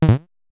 error_006.wav